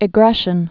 (ĭ-grĕshən)